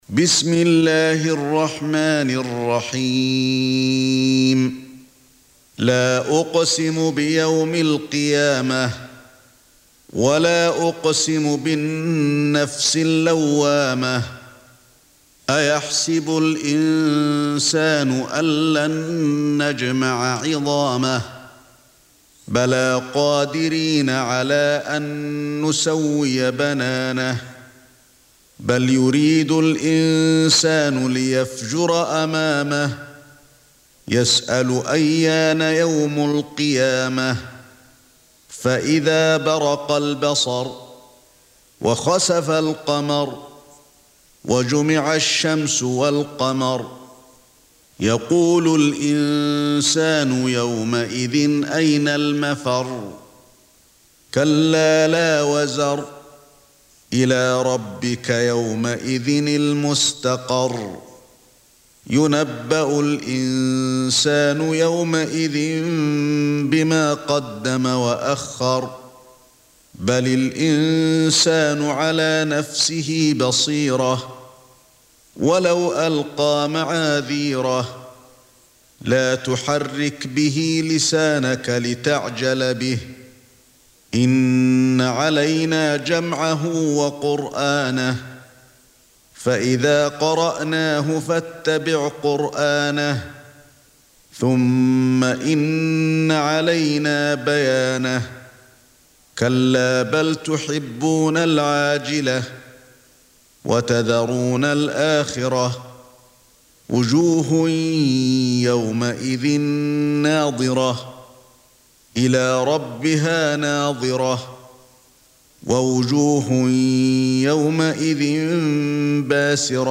Surah Sequence تتابع السورة Download Surah حمّل السورة Reciting Murattalah Audio for 75. Surah Al-Qiy�mah سورة القيامة N.B *Surah Includes Al-Basmalah Reciters Sequents تتابع التلاوات Reciters Repeats تكرار التلاوات